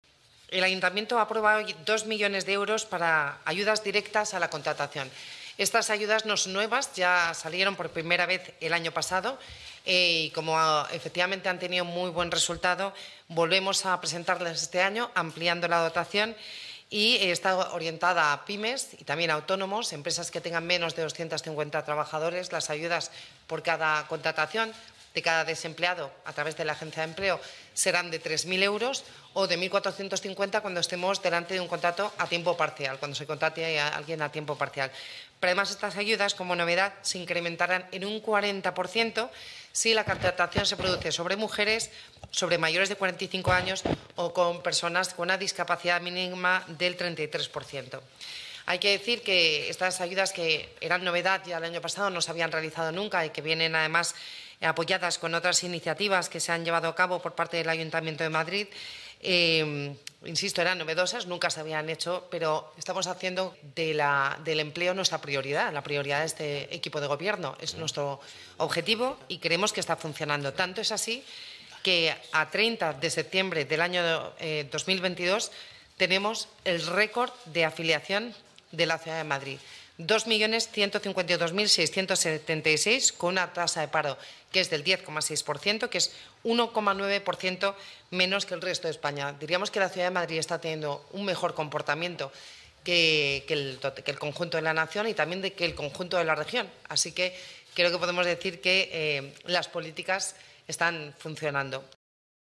Nueva ventana:Declaraciones de la vicealcaldesa de Madrid, Begoña Villacís: